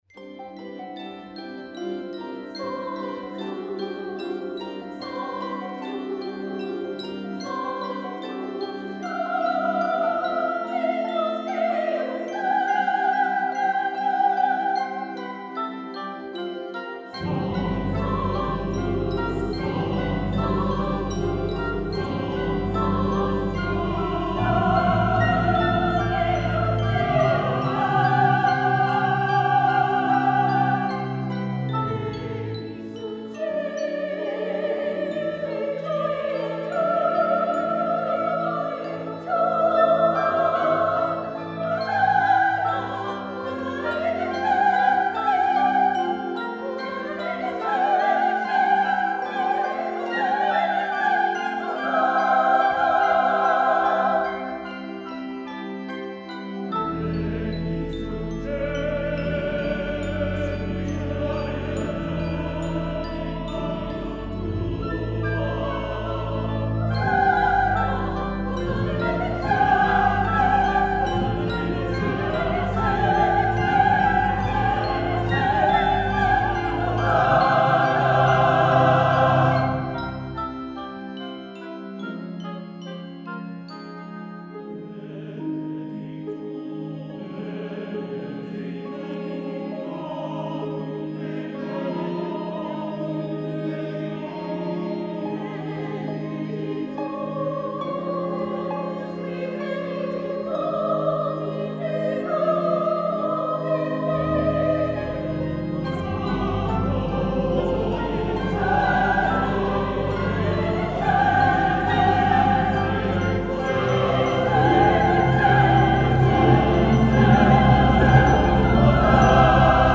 The North Valley Chorale (NVC) delivered another radiant concert on Feb 25 at the Church of the Beatitudes.
soprano